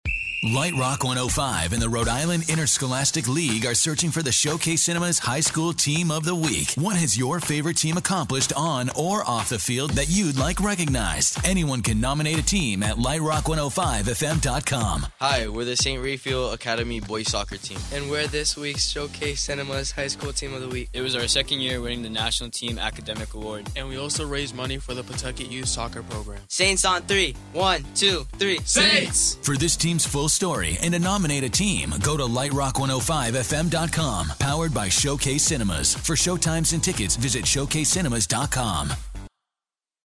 Lite Rock 105 On-Air Spot